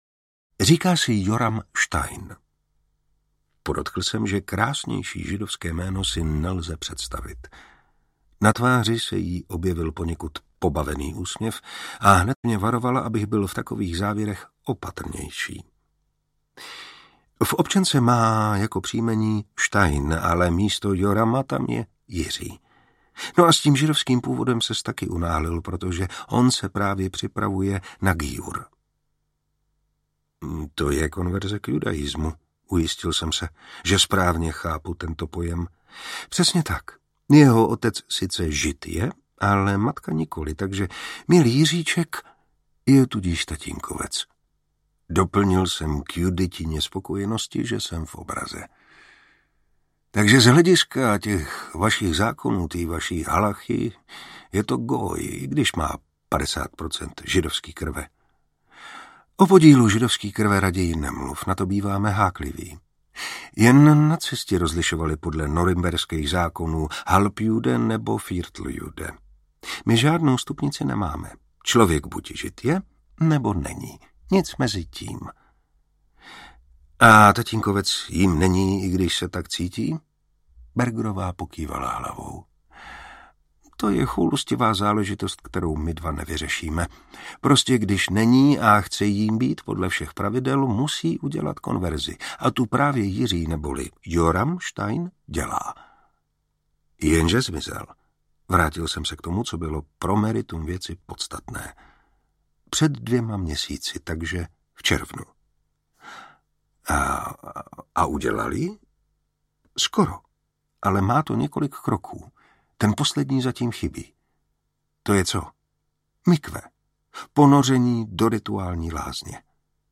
Smrt konvertity audiokniha
Ukázka z knihy
Čte Martin Preiss.
Vyrobilo studio Soundguru.